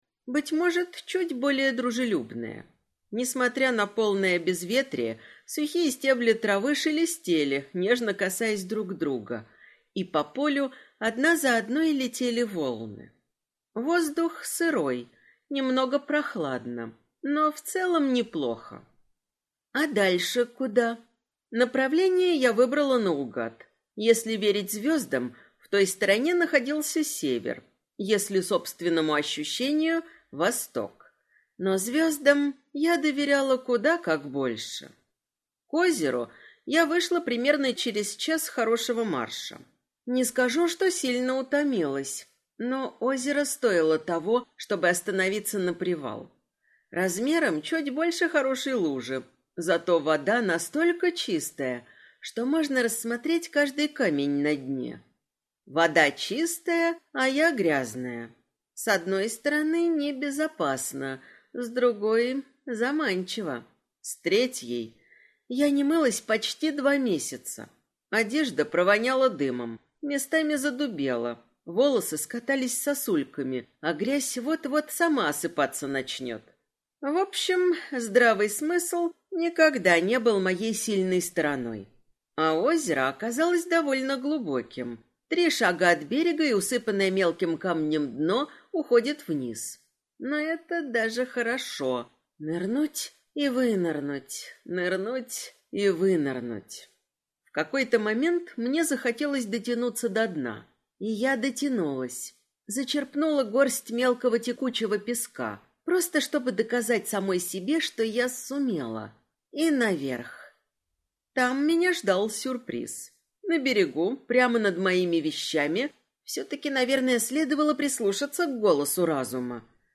Аудиокнига Книга цены. Книга 2 | Библиотека аудиокниг